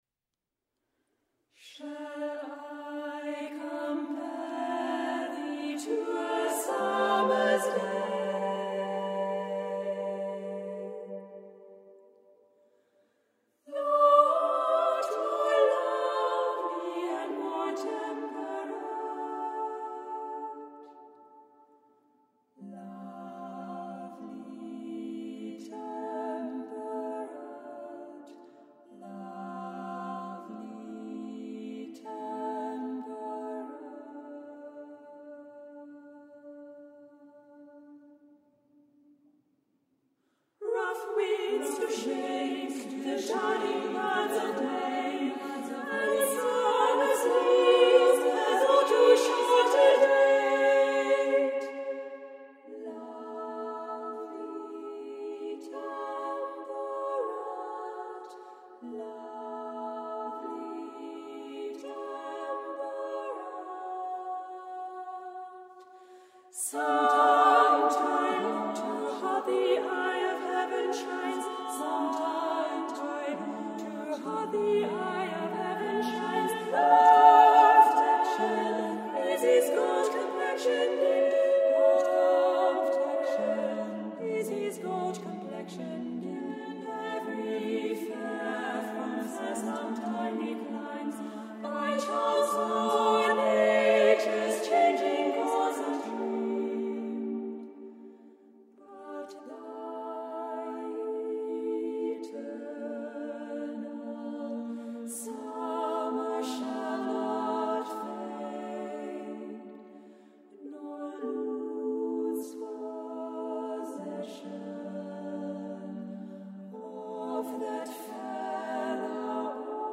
Compositie voor dameskoor.
CYCLE A CAPPELLA SSAAA.